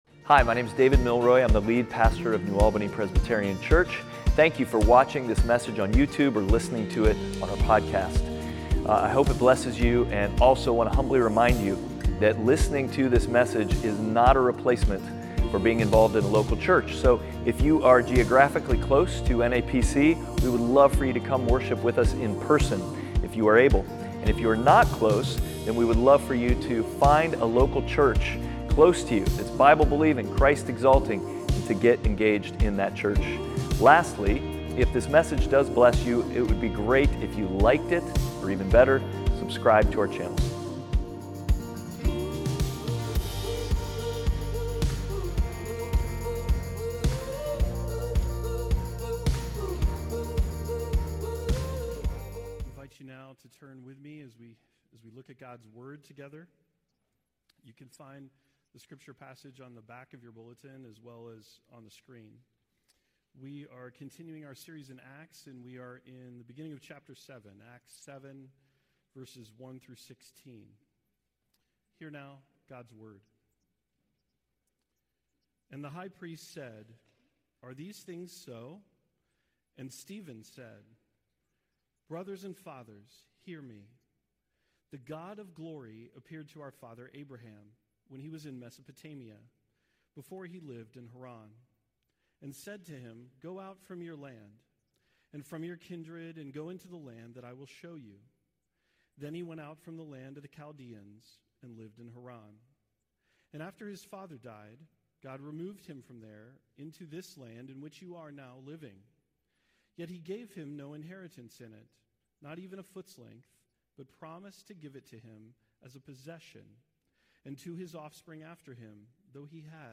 Passage: Acts 7:1-16 Service Type: Sunday Worship